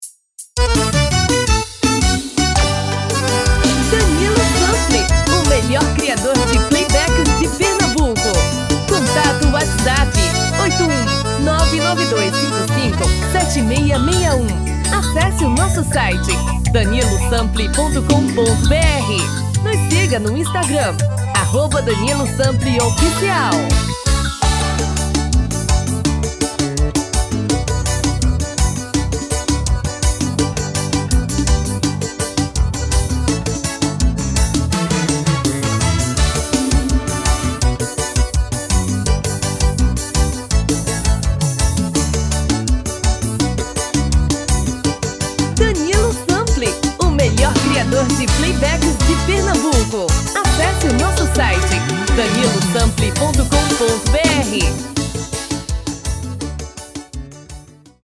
DEMO 1: tom original DEMO 2: um tom acima